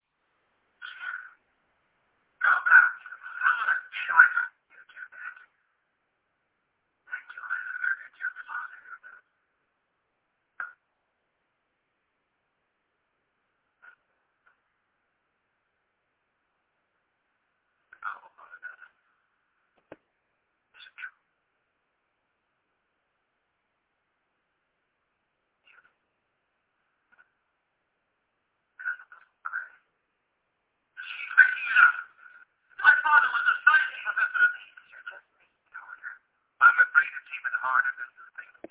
Quality is low, but the rarity of these materials makes them an absolute treasure.